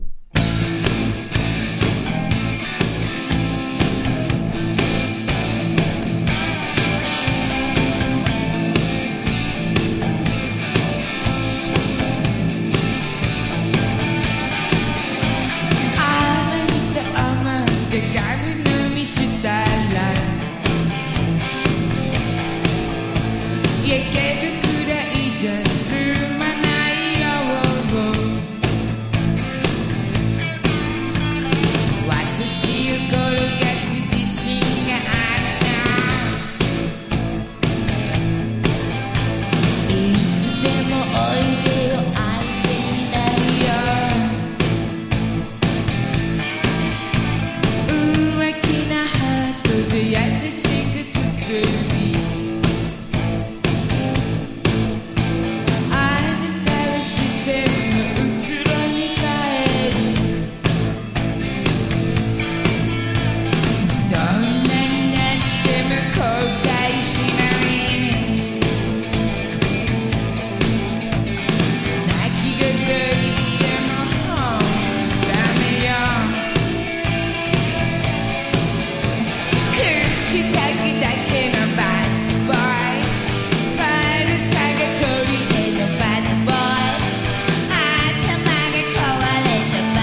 # 60-80’S ROCK# POP# 和モノ
• 盤面 : EX+ (美品) キズやダメージが無く音質も良好